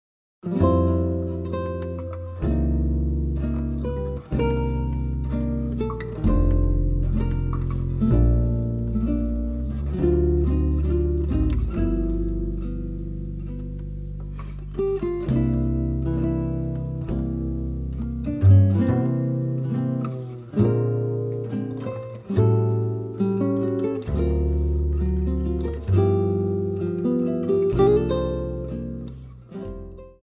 acoustic seven-string guitar
electric guitar, vocals
pedal steel guitar
acoustic bass
drums